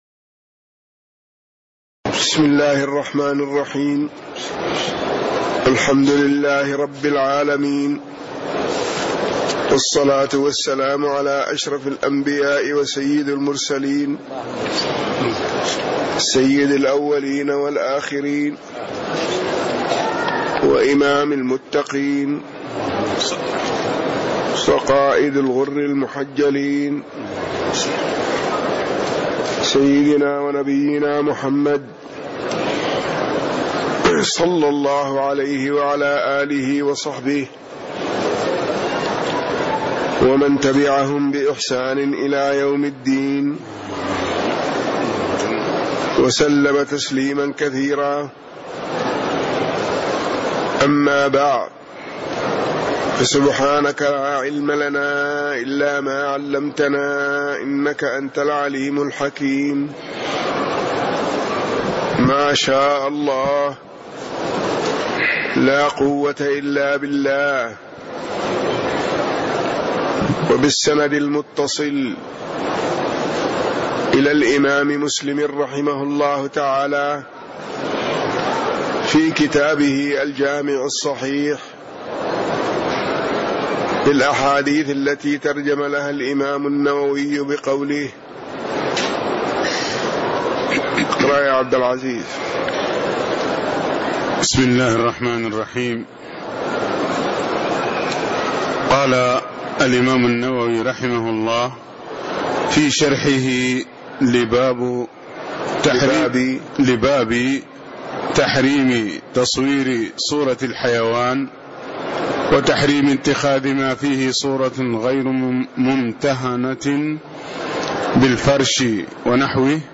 تاريخ النشر ٢٠ شوال ١٤٣٦ هـ المكان: المسجد النبوي الشيخ